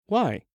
알림음 8_Why1.mp3